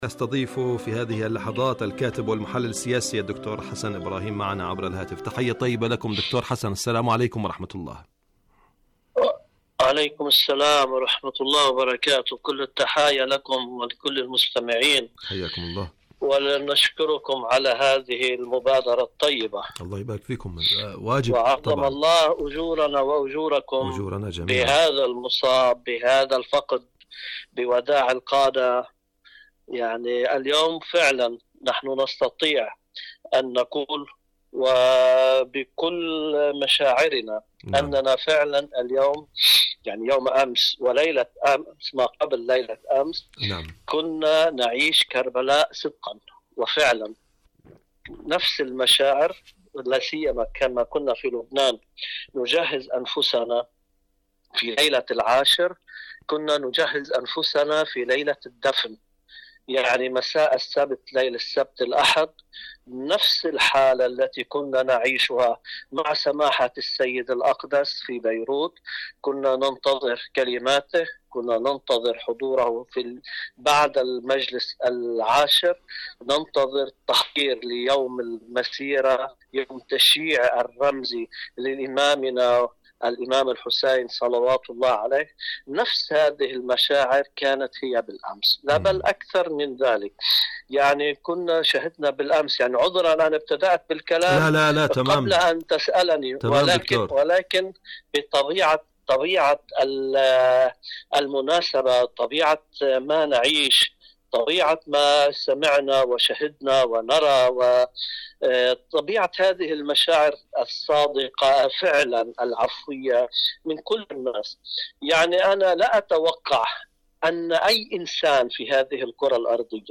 إذاعة طهران- فلسطين اليوم: مقابلة إذاعية